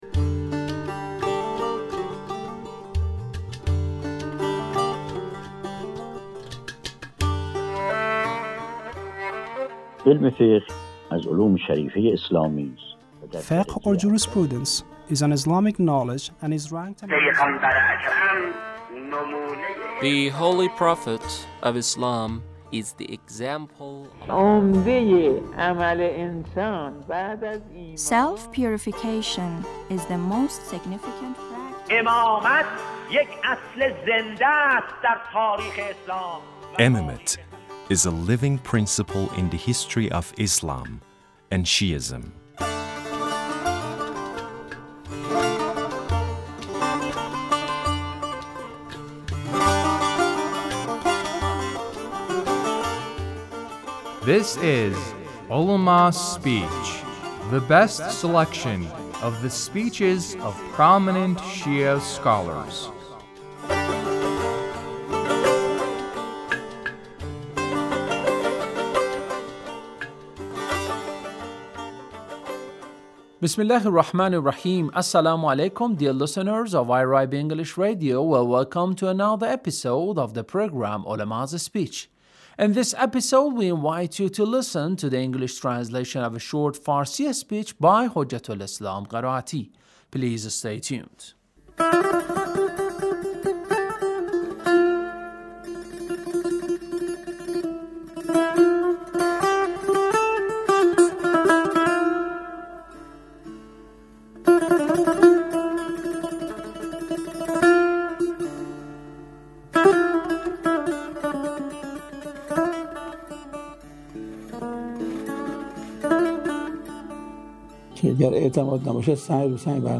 Ulemas' Speech (1553)